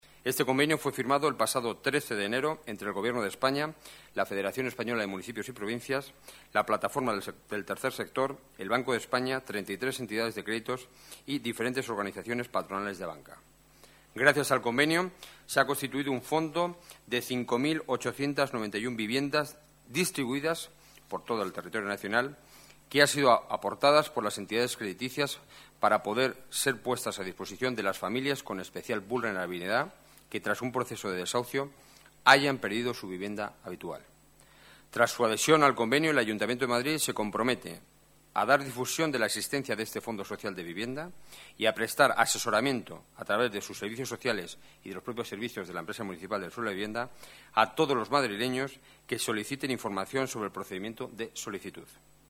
Nueva ventana:Declaraciones del portavoz del Gobierno municipal, Enrique Núñez